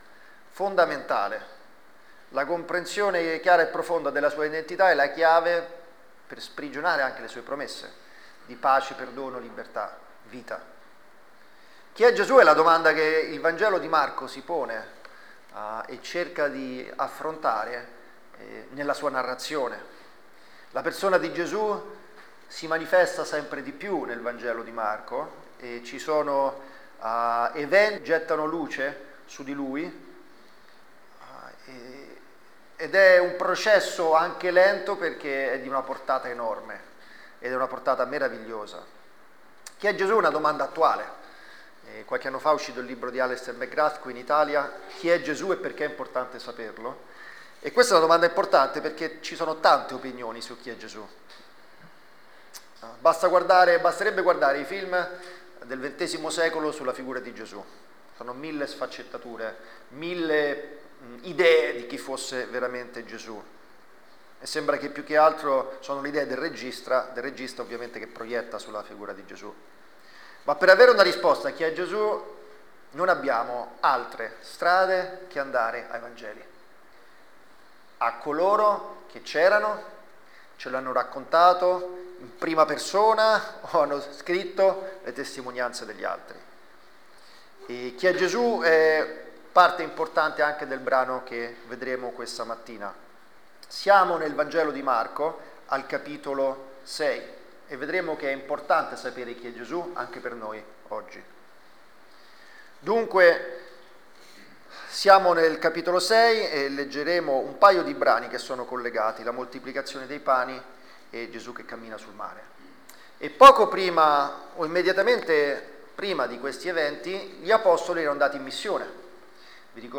Insegnamenti dal vangelo di Marco 6:30-52